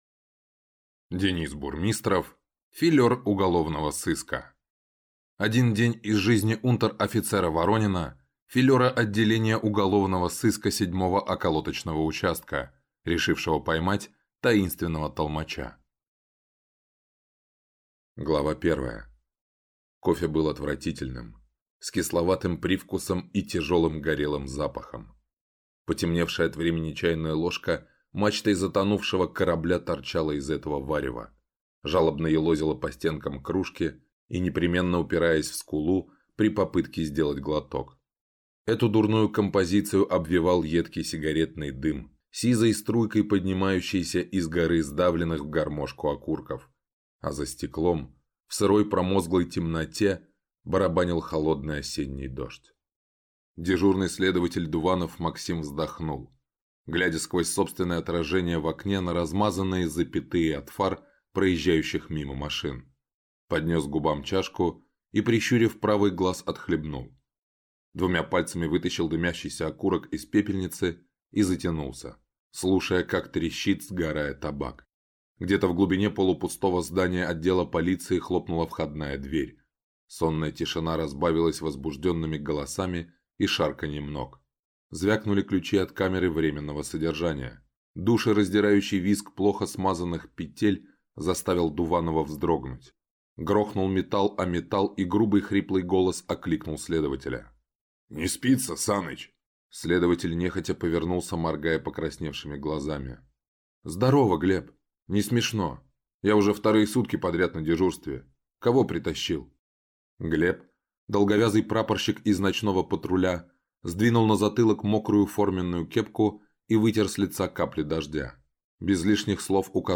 Аудиокнига Филер уголовного сыска | Библиотека аудиокниг